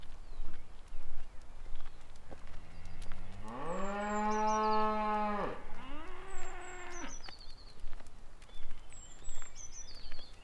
cow_moo.wav